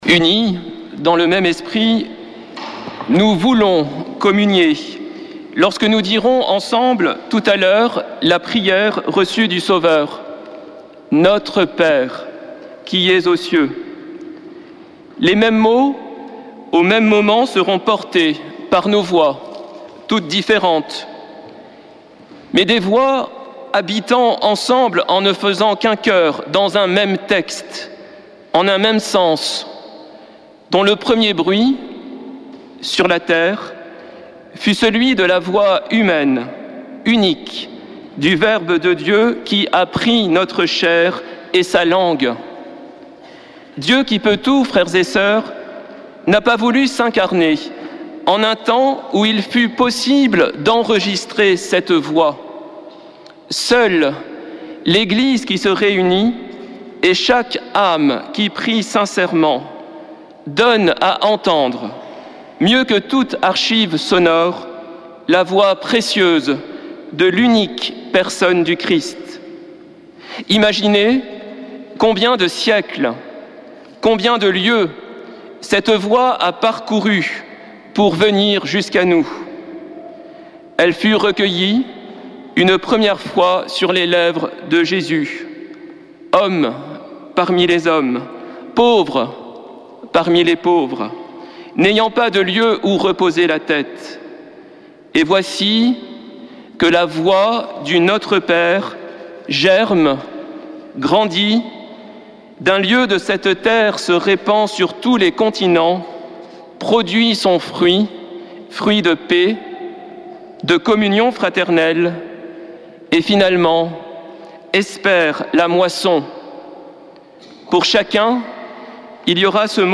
Messe depuis le couvent des Dominicains de Toulouse
Homélie du 16 juin